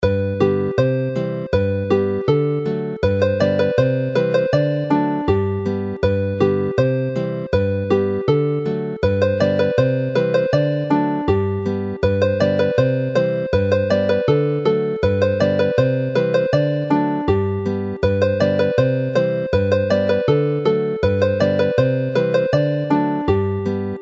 Alawon Cymreig - Set yr Aradr - Welsh folk tunes to play